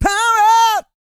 E-GOSPEL 125.wav